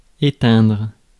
Ääntäminen
IPA : /pʊt aʊt/